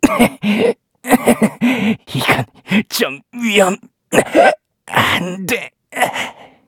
Kibera-Vox_Dead_kr.wav